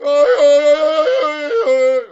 tarzan_yell.wav